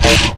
anomaly_gravy_hit.ogg